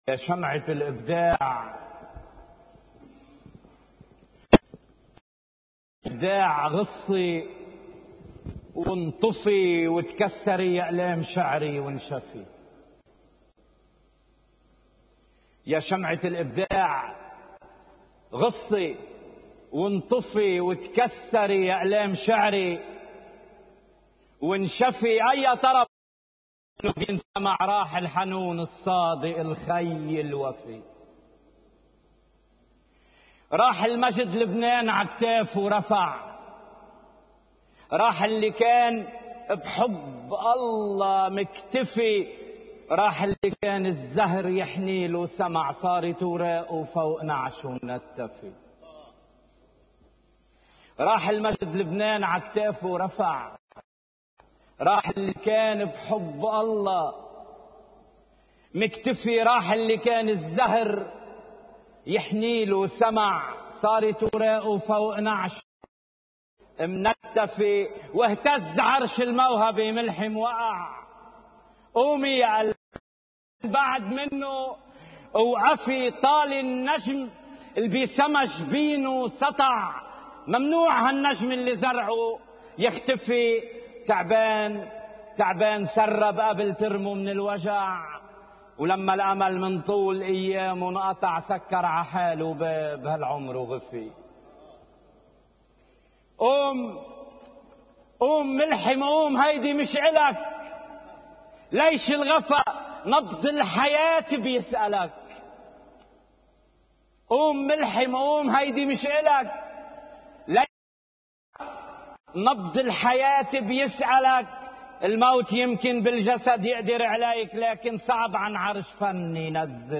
كلمة وداعية مؤثرة من الشاعر نزار فرنسيس للراحل الكبير الموسيقار ملحم بركات (Video+Audio)